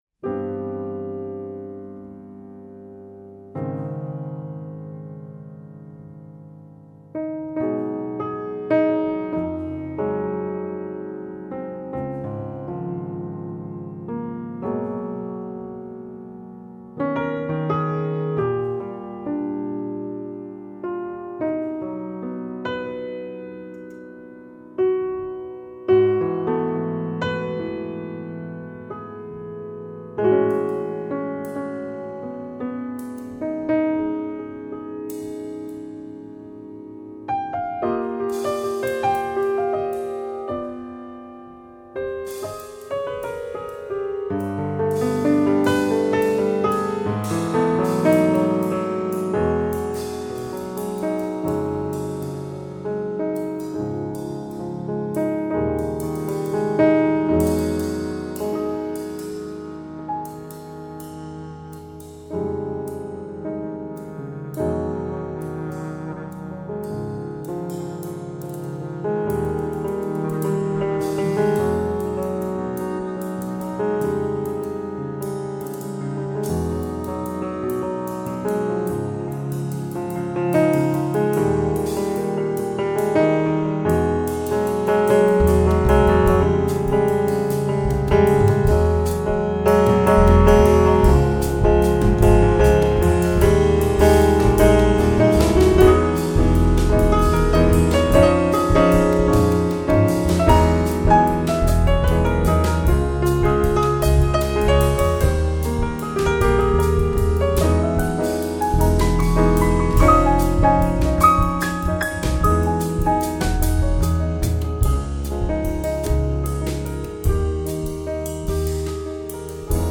Piano
Double Bass